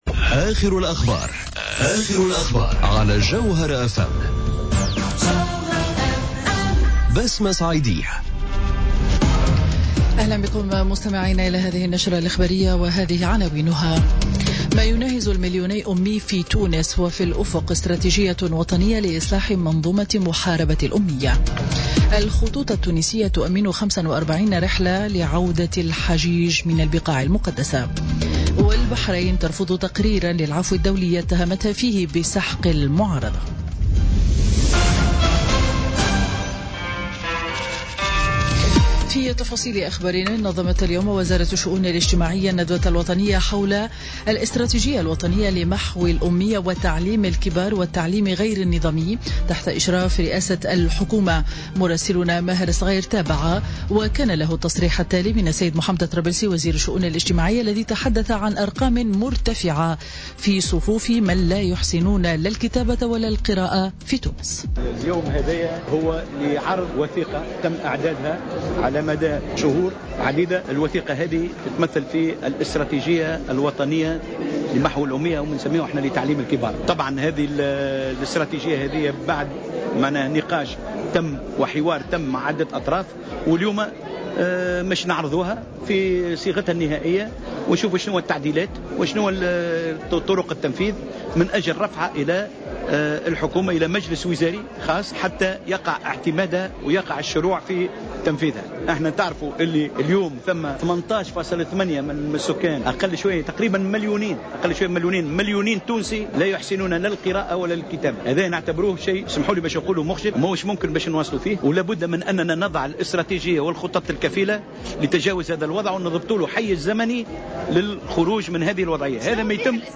نشرة أخبار منتصف النهار ليوم الجمعة 8 سبتمبر 2017